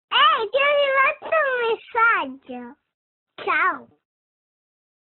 Categoria Messaggio